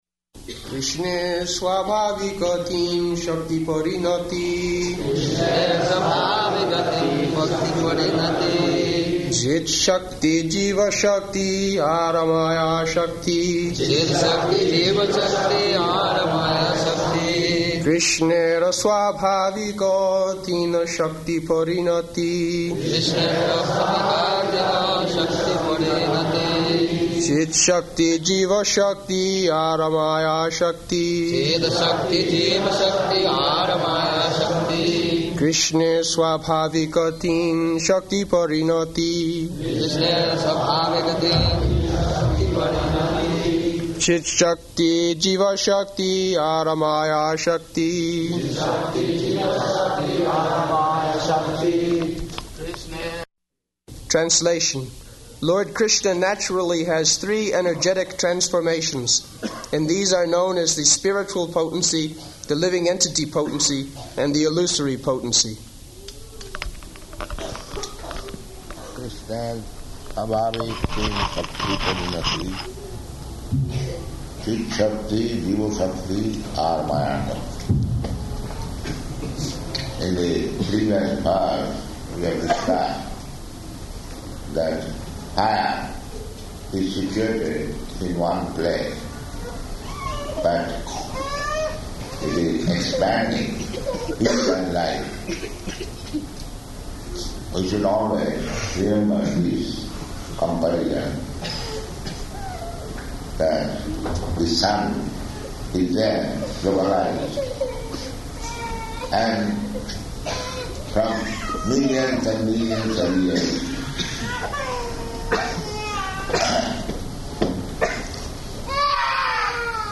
July 19th 1976 Location: New York Audio file
[devotees repeat] [break] kṛṣṇera svābhāvika tina-śakti-pariṇati cic-chakti jīva-śakti āra māyā-śakti [ Cc.
We should always remember this comparison, that the sun is there, localized, and from millions and millions of years [child crying loudly] it is distributing heat and light.